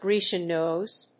アクセントGrécian nóse